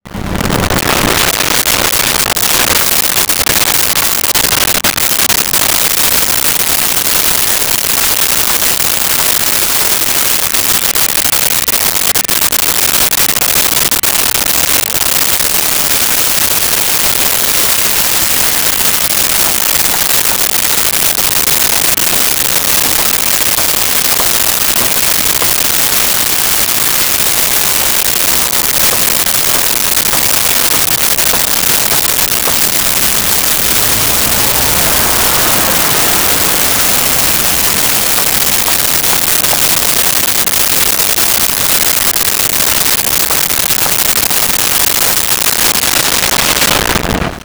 Construction Site
Construction Site.wav